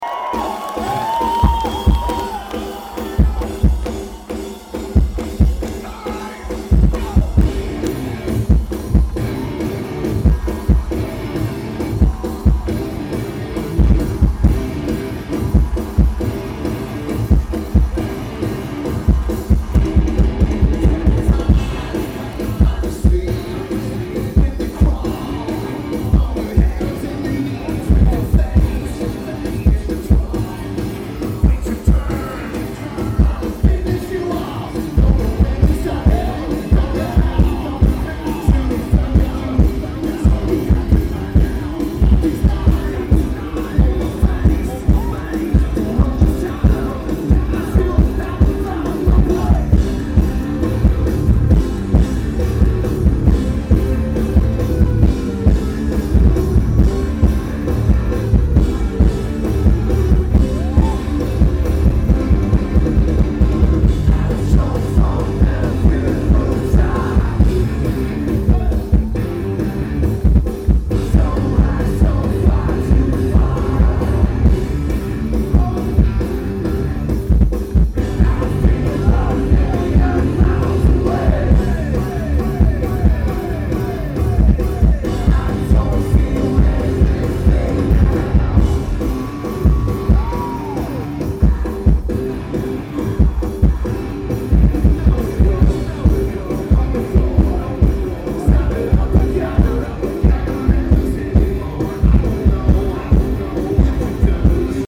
Heineken Music Hall
Lineage: Audio - AUD (Edirol R09 + Internal Mics)